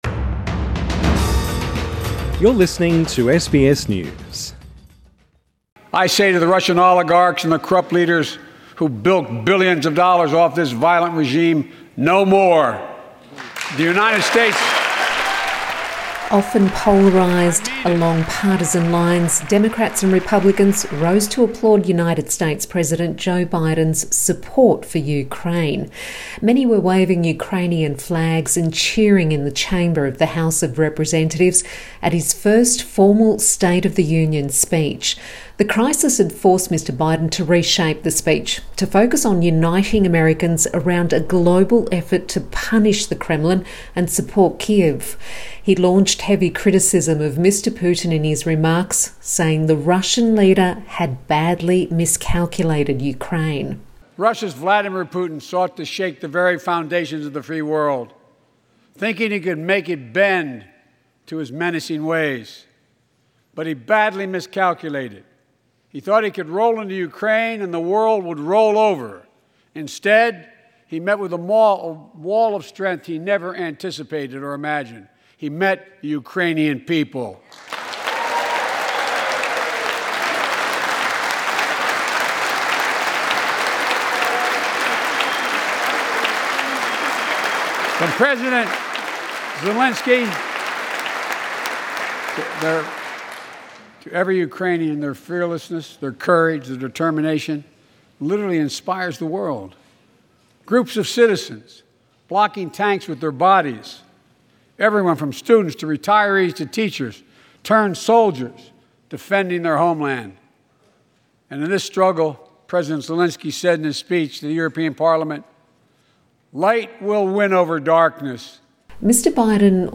Joe Biden delivers his first State of the Union address
He was speaking at the State of the Union address on Tuesday which was rewritten to target Mr Putin for his invasion of Ukraine.